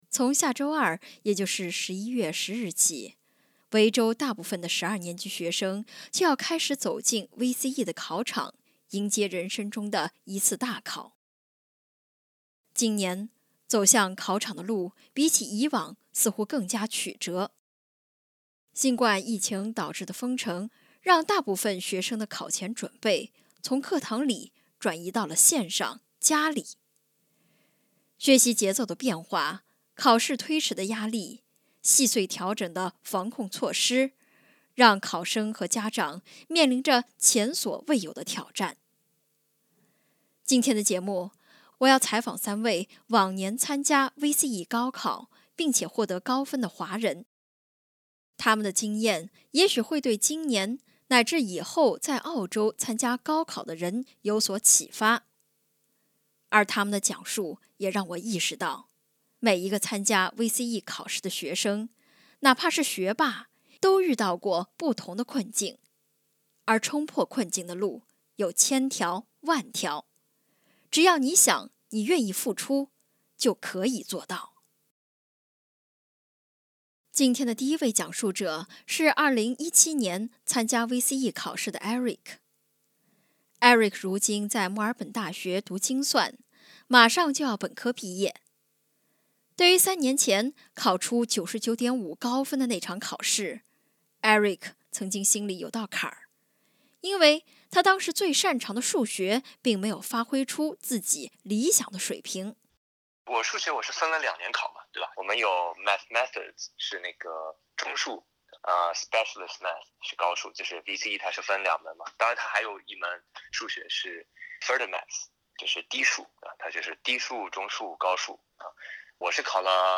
接受SBS中文普通话采访的多名往届学霸都认为：调节好心态很重要。